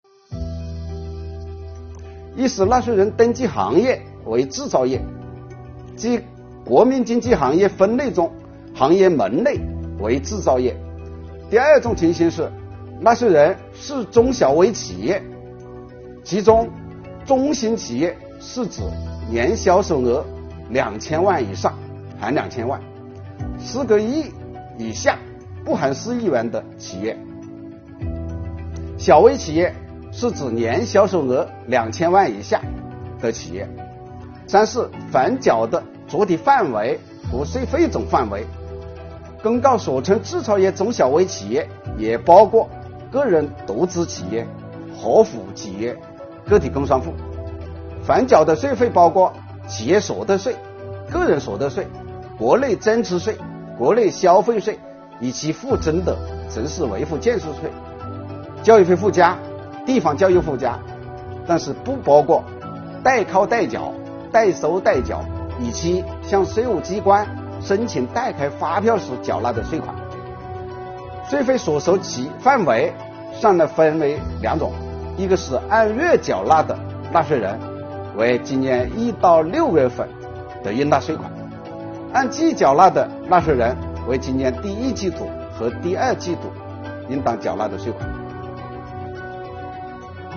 本期课程国家税务总局征管和科技发展司副司长付扬帆担任主讲人，对制造业中小微企业缓缴税费政策解读进行详细讲解，确保大家能够及时、便利地享受政策红利。